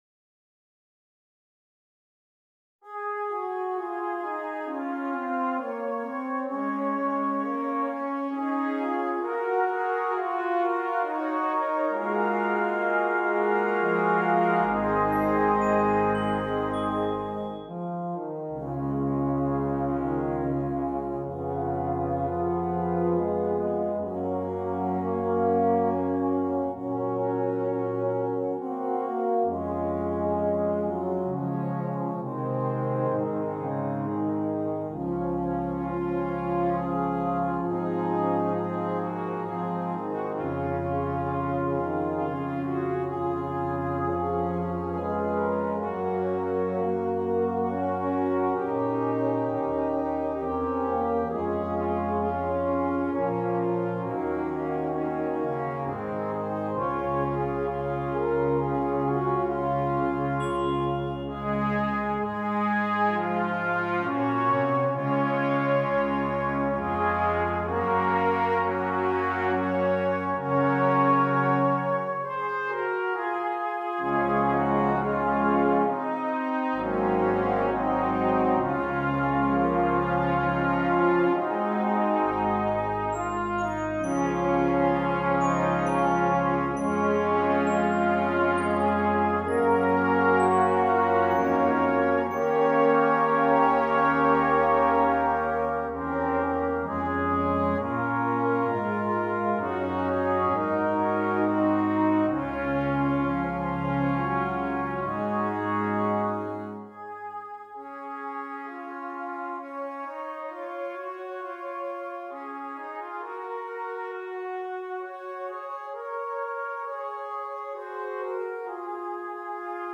Brass Choir (4.4.3.1.1.perc)